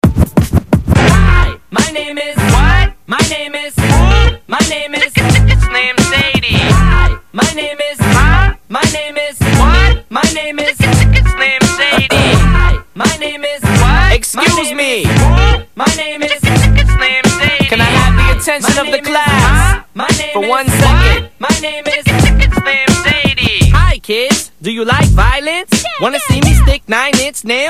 illustrating the use of various sounds and sampling
the use of a psychedelic sounds
The quality has been reduced to meet WP:SAMPLE.